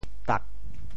« 妲 » quel est le mot en Teochew ?